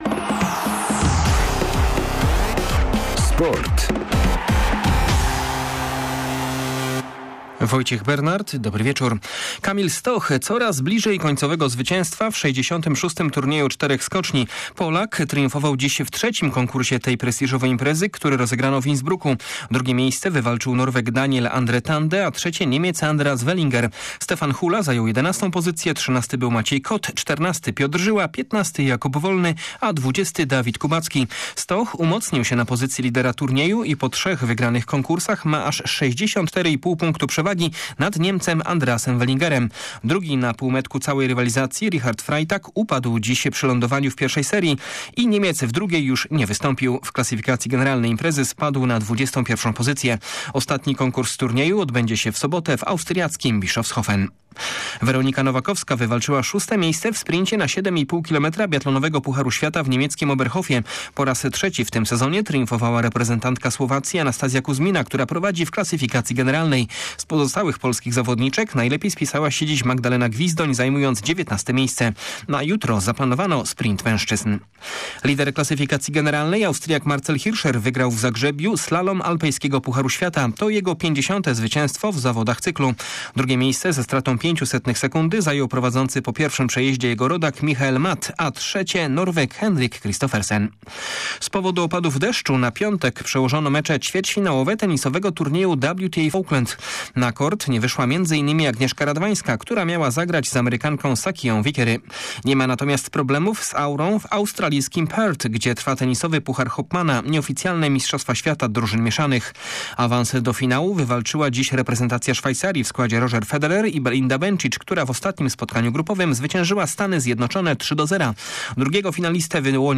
04.01 serwis sportowy godz. 19:05